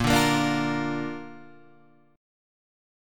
A# Major